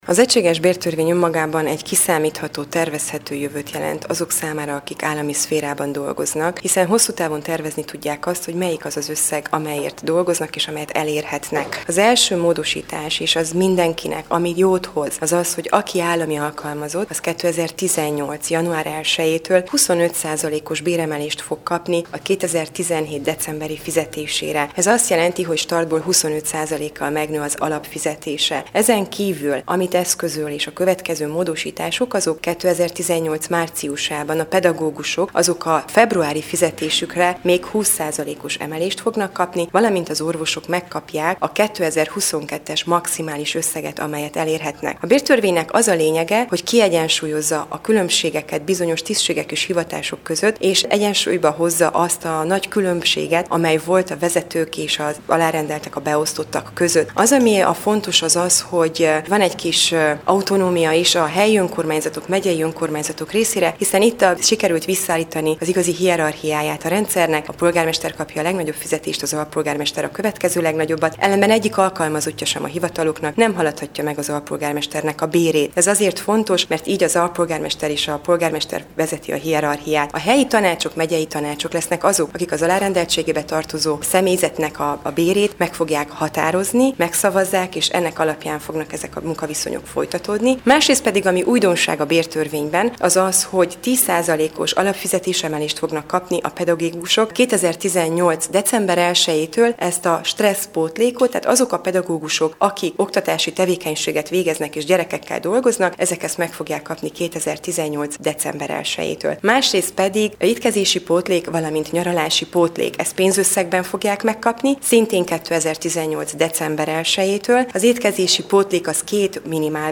Az egységes bértörvényről tartott ma sajtótájékoztatott Csép Éva Andrea, a Szövetség Maros megyei parlamenti képviselője, a képviselőház munkaügyi bizottságának titkára. Mint ismeretes megszavazta tegnap az RMDSZ képviselőházi frakciója az egységes bértörvény-tervezetet, amely jövő év január elsejétől 25 százalékkal növeli az állami alkalmazottak bérét, így kiszámíthatóbb és következetesebb bérezési rács alapján biztosít méltányos fizetéseket a közigazgatásban dolgozóknak.